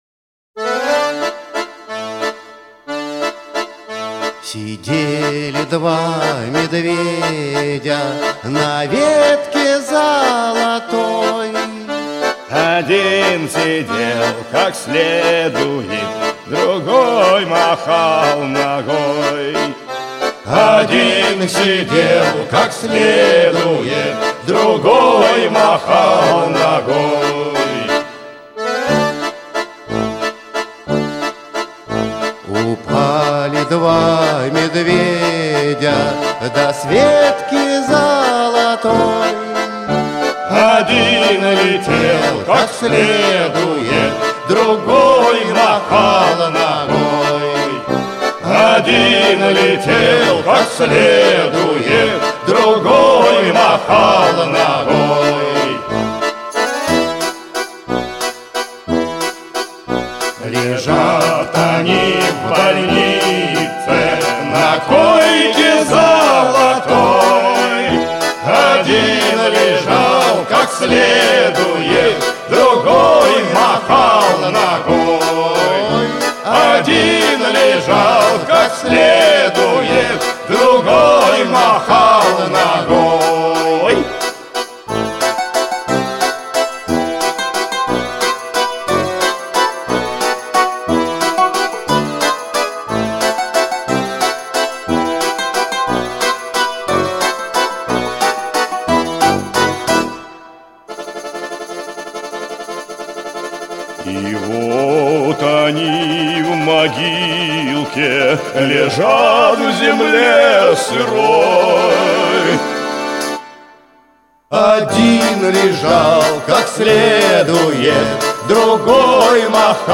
• Качество: Хорошее
• Категория: Детские песни
народный мотив